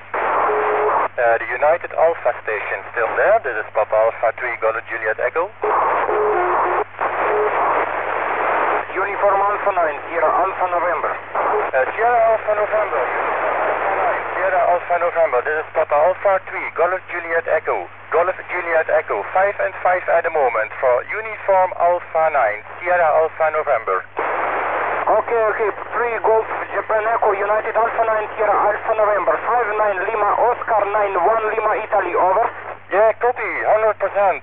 Satellite QSO ( Some very short Audio Fragments concerning Firsts via Satellites )
Remark : the taperecorder had been connected ( directly ) to the Alinco tranceiver DR 510E ( concerning UO-14 and AO-27 in mode J ).
Apologies because of the audio quality.
Further, the taperecorder was very noisy. Because of the compression, most ( mono ) audio files ( sample rate = 6000, resolution = 8 bits ) delivered a bad signal noise ratio.